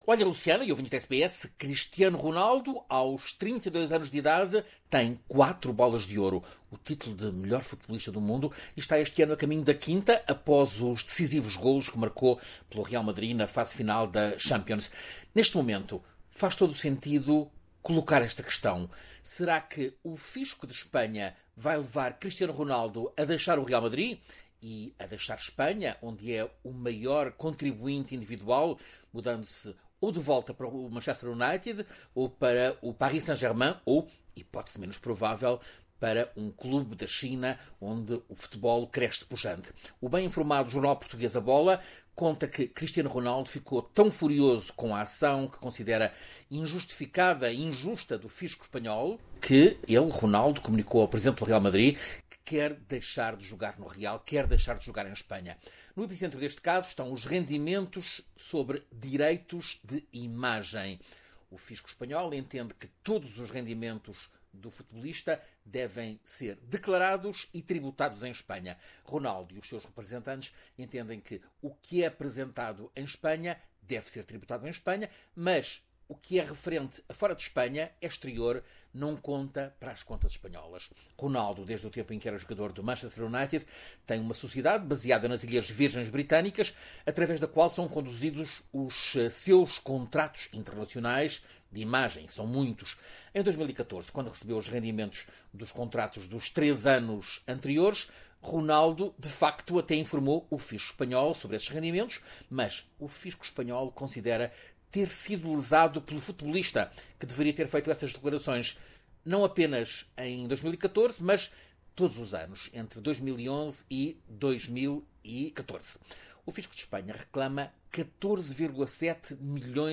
Cristiano Ronaldo está furioso por ser chamado de "criminoso fiscal" e o Manchester United pode estar outra vez nos caminhos do craque português. Reportagem